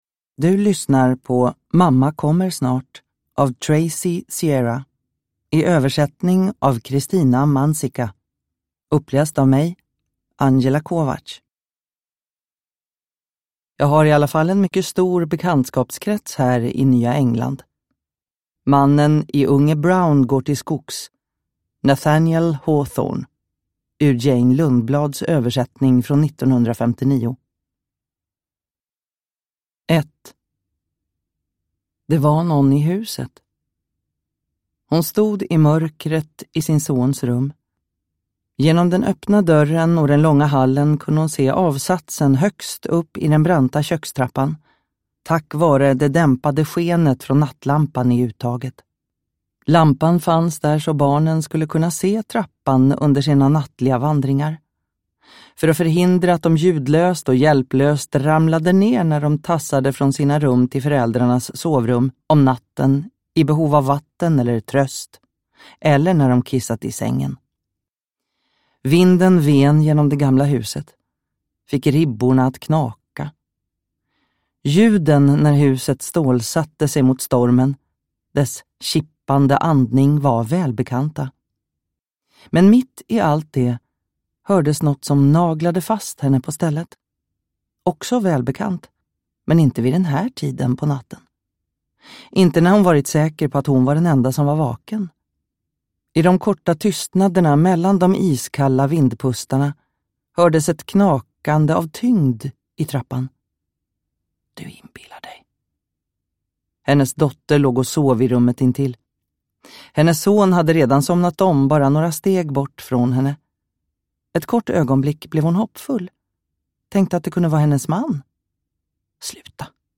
Mamma kommer snart – Ljudbok